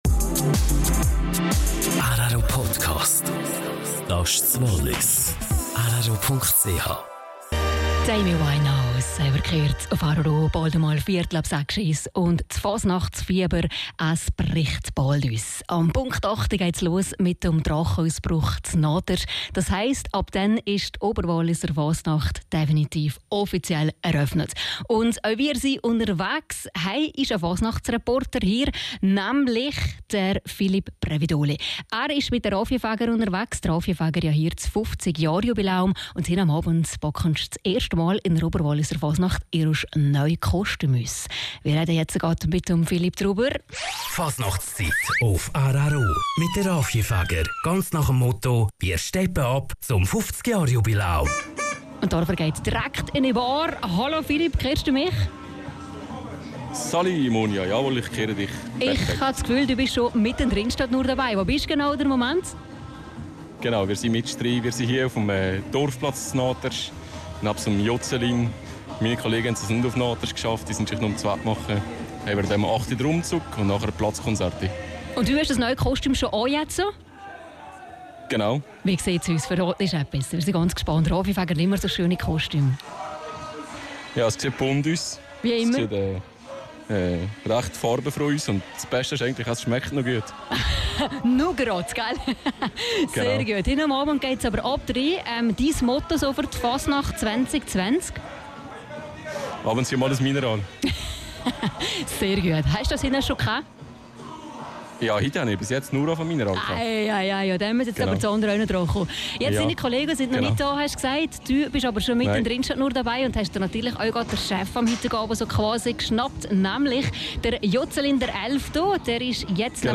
Rückblick auf den 51. Drachenausbruch in Naters.